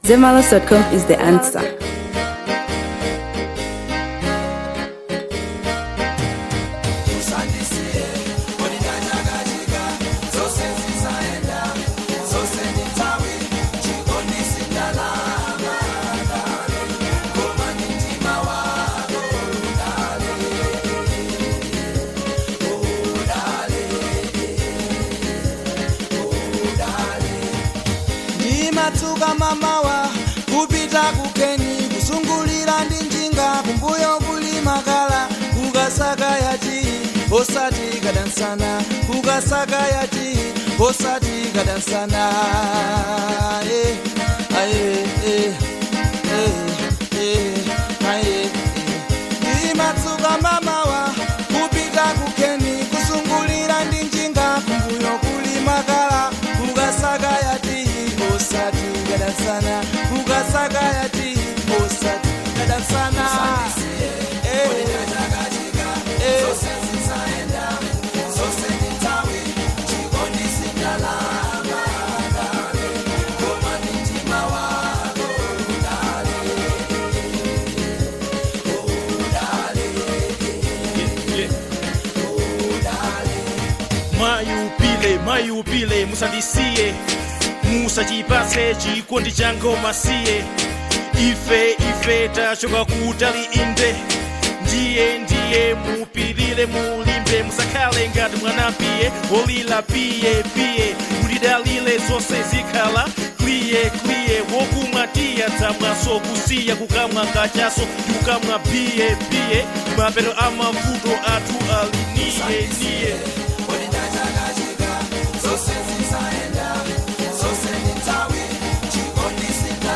Fusion • 2025-10-27